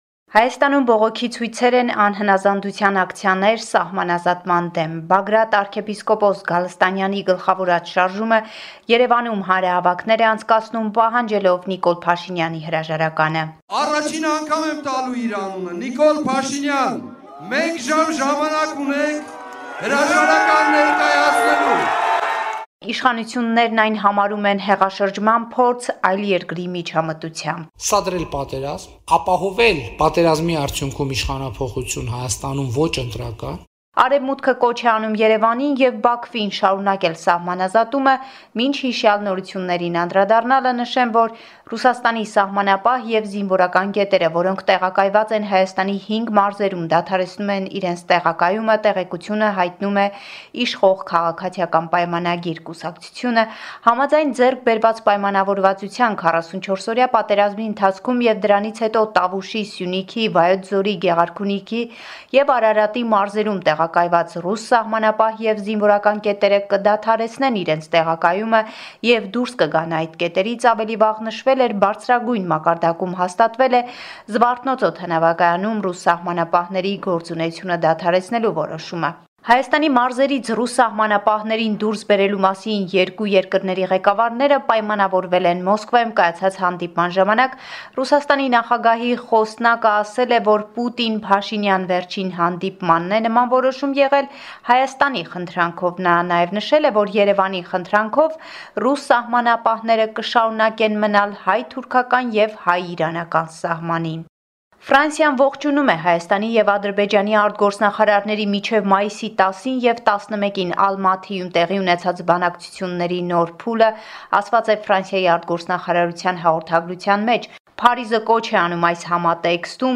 Latest news from Armenia, Artsakh and the Diaspora from our reporter